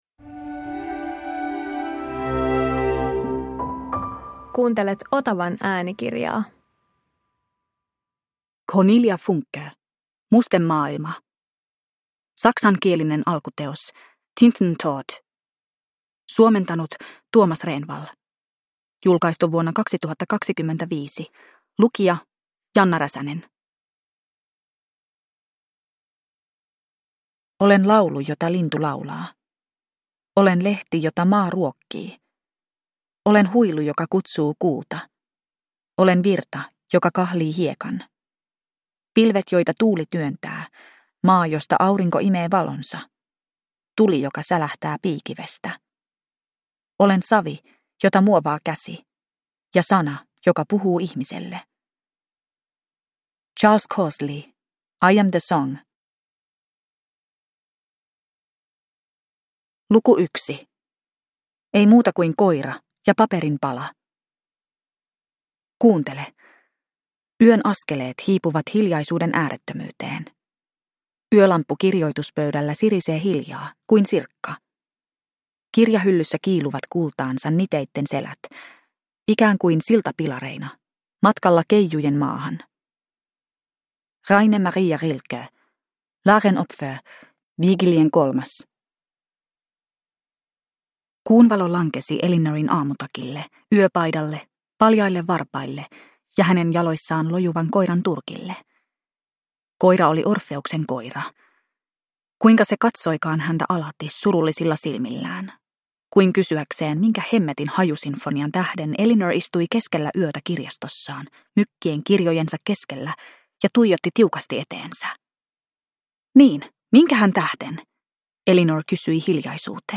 Mustemaailma – Ljudbok